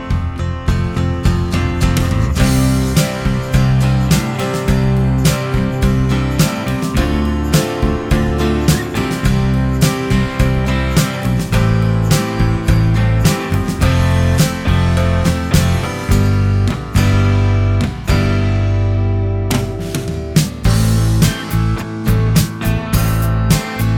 no Backing Vocals Soundtracks 4:18 Buy £1.50